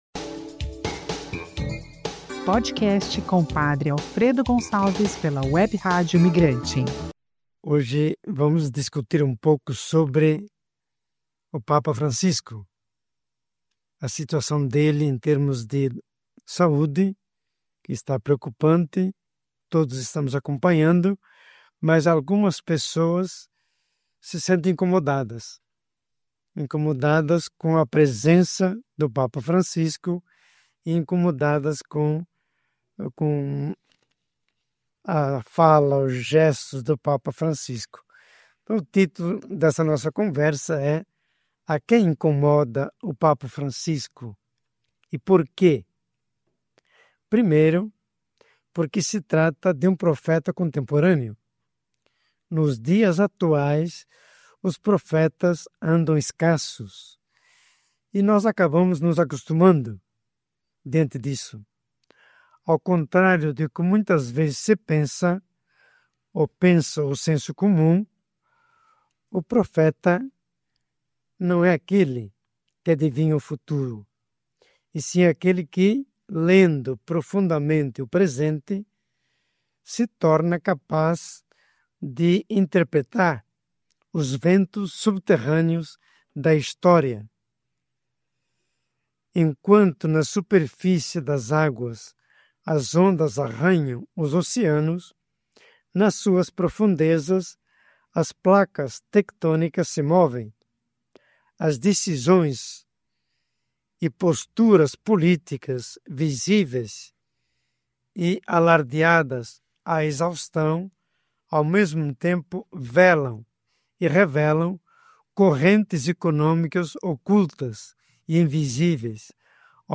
Gravado e editado nos Estudios da web Radio Migrante Arte